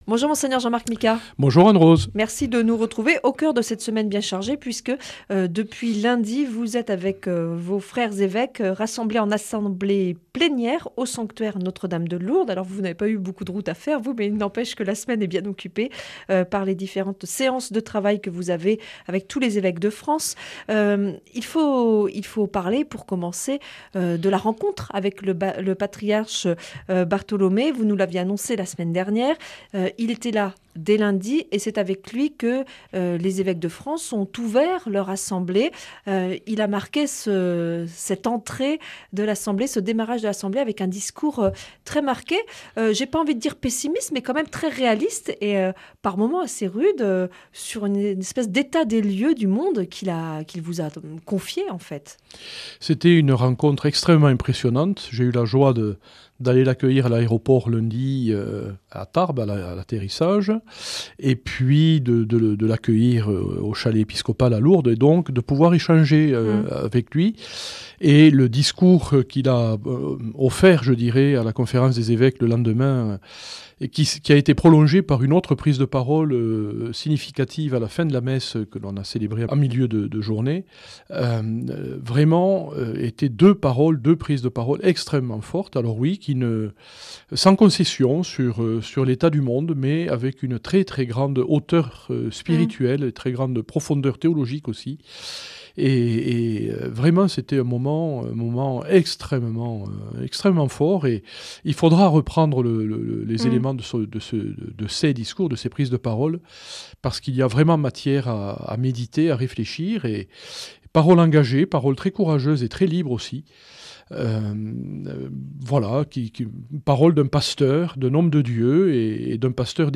Entretien avec Mgr Micas - Évêque de Tarbes Lourdes